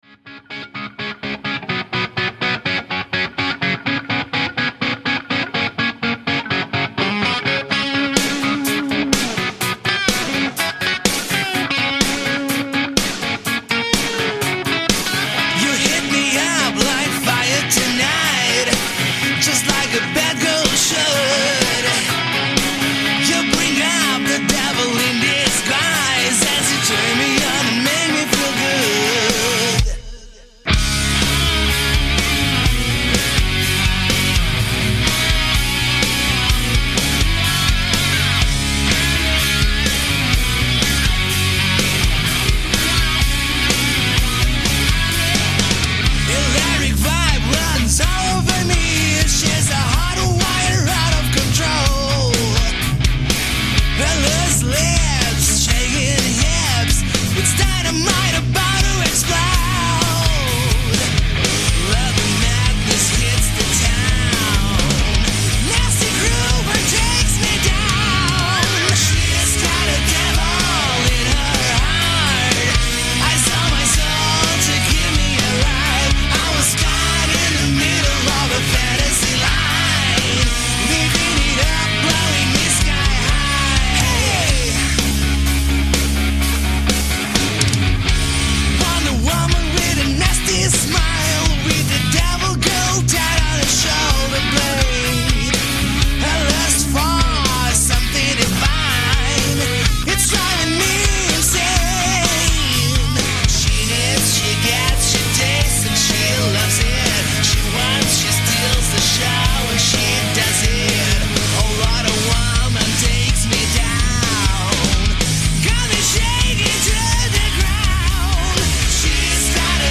This radio show is a cool mix of Portuguese rock and pop